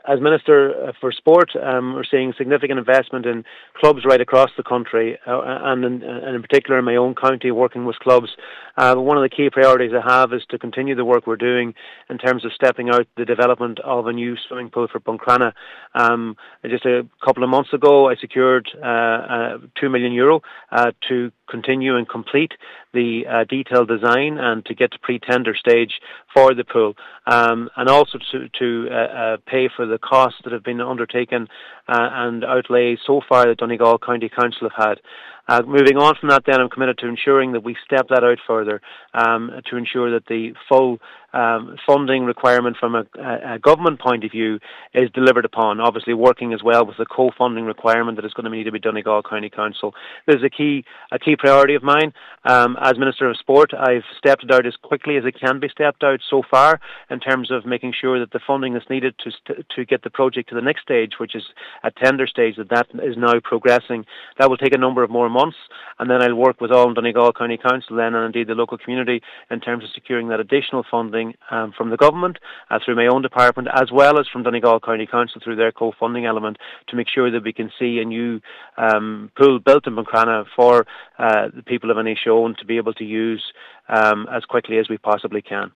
Minister McConalogue says he remains fully committed to seeing the project delivered for the people of Inishowen, who have been without a pool since 2015: